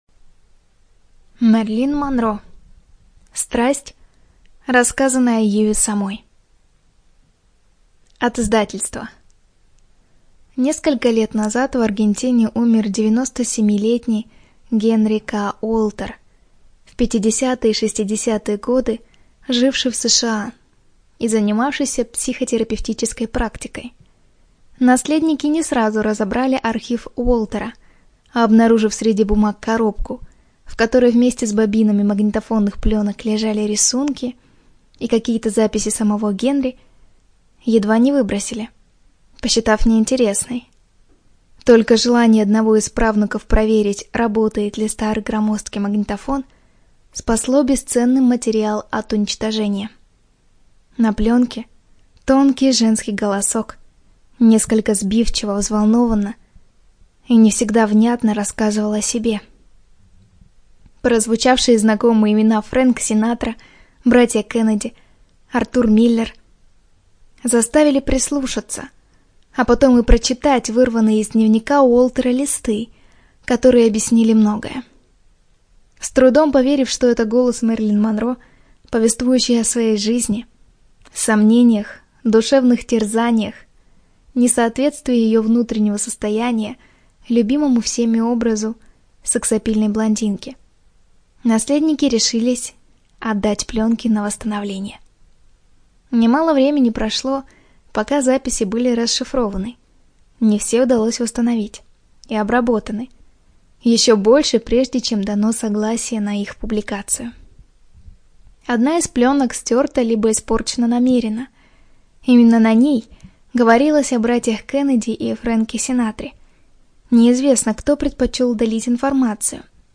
ЖанрБиографии и мемуары